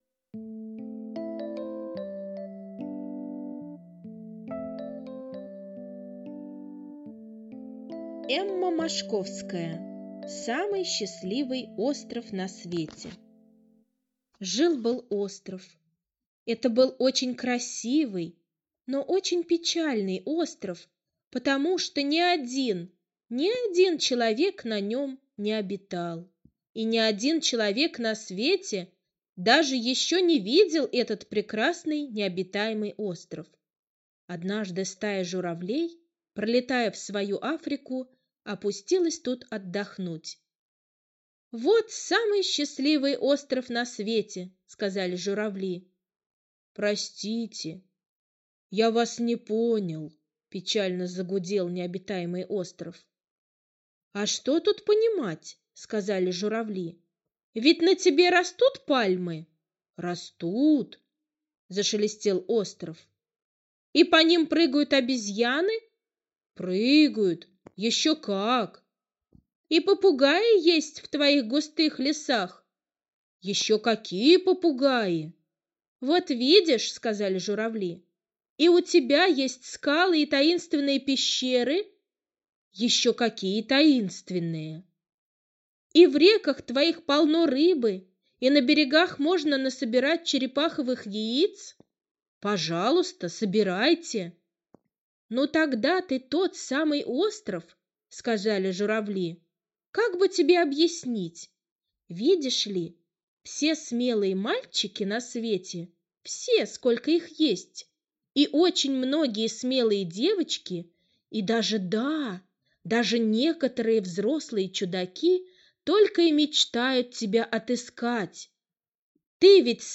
Аудиосказка «Самый счастливый Остров на свете»